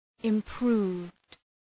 {ım’pru:vd}
improved.mp3